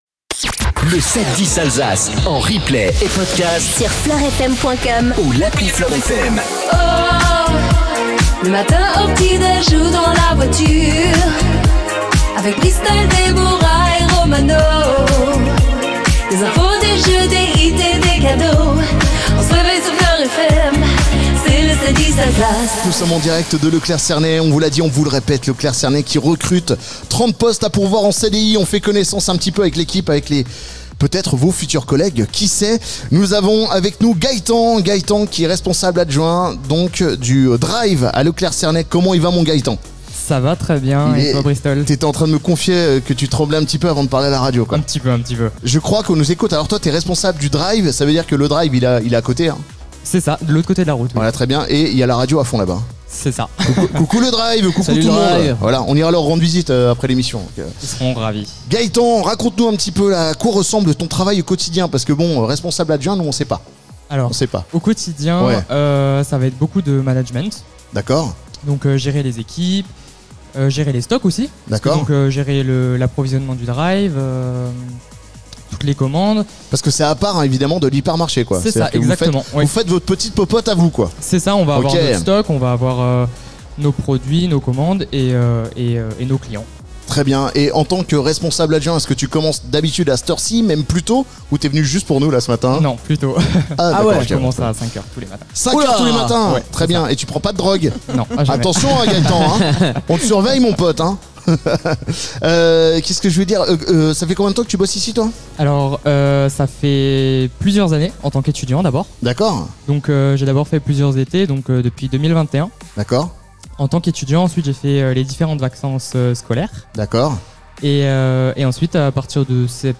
LE 7-10 ALSACE CHEMINETTE E. LECLERC CERNAY FLOR FM Vendredi 25 octobre 0:00 20 min 53 sec 25 octobre 2024 - 20 min 53 sec LE 7-10 ALSACE DU 25 OCTOBRE Retrouvez les meilleurs moments du 7-10 Alsace en direct de E. Lerclerc Cernay , avec Chemi'nette à Niederhergheim.